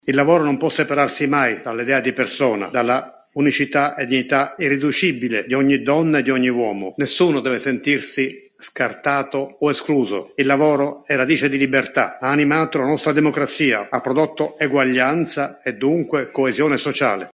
Non si può parlare di lavoro senza parlare di diritti, non si può festeggiare il Primo Maggio senza richiamare l’attenzione su alcuni temi su cui ancora bisogna insistere: la sicurezza, l’uguaglianza, lo sfruttamento. Lo ha ricordato con puntualità il Presidente della Repubblica Sergio Mattarella alla vigilia della giornata odierna: